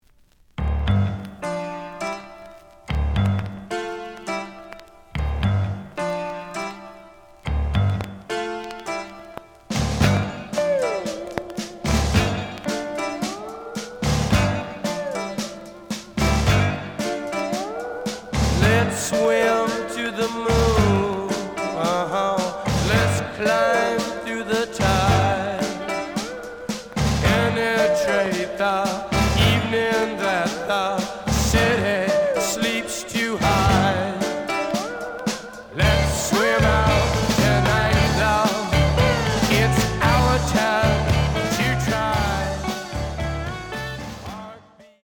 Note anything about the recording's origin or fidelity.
The audio sample is recorded from the actual item. Some click noise on B side due to scratches.)